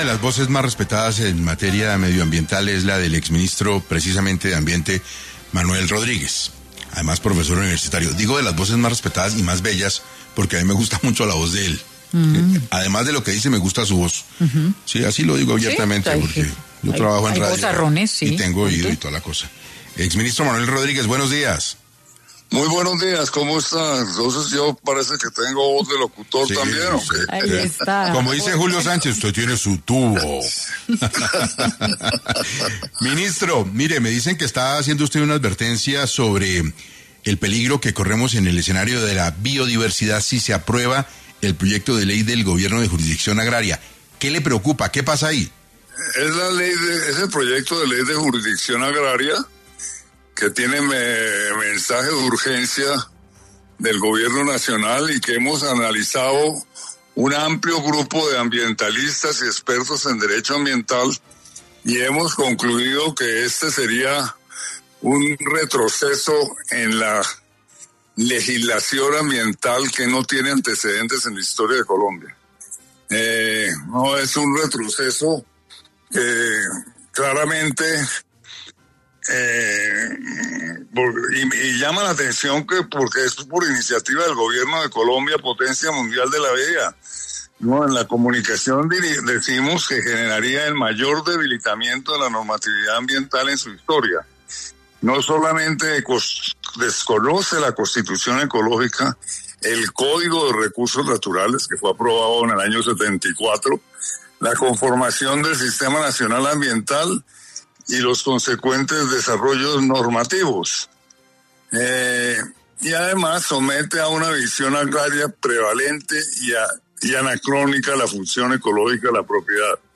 En Caracol Radio estuvo Manuel Rodríguez, exministro de Ambiente